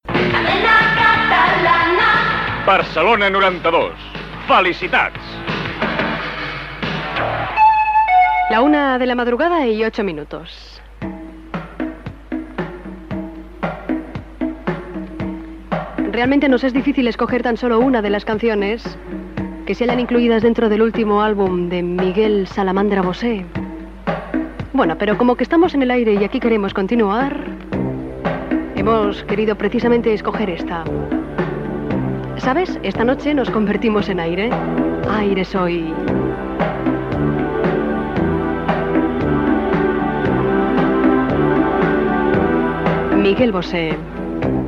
Indicatiu Cadena Catalana-Barcelona 92, hora, i presentació d'un tema musical .
Entreteniment